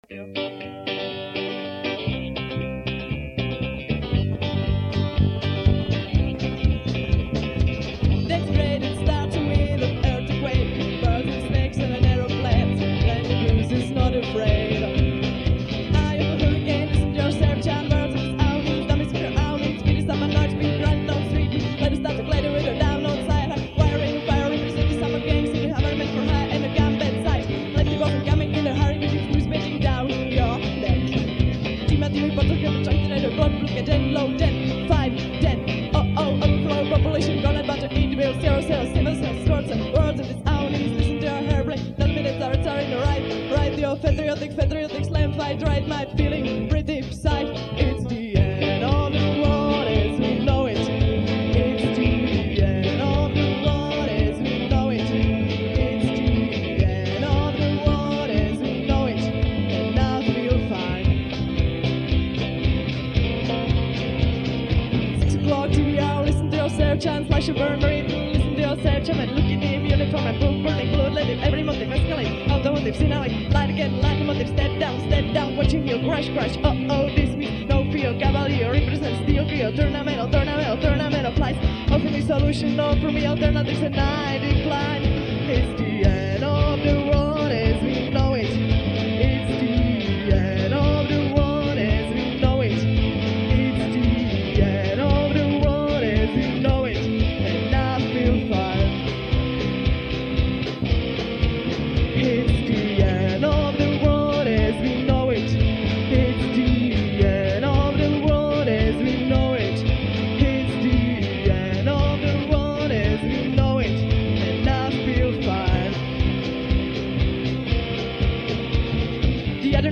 nahrávky z koncertu (.mp3):